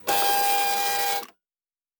pgs/Assets/Audio/Sci-Fi Sounds/Mechanical/Servo Small 10_1.wav at master
Servo Small 10_1.wav